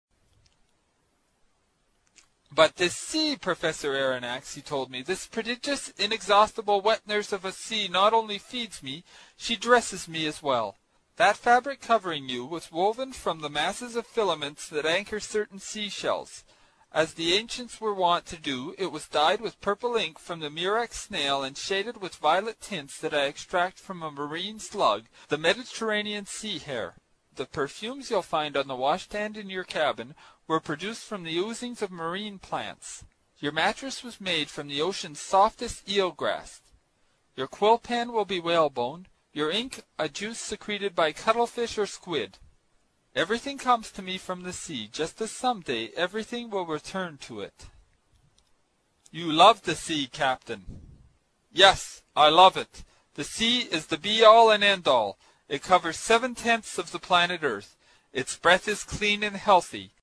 英语听书《海底两万里》第147期 第10章 水中人(18) 听力文件下载—在线英语听力室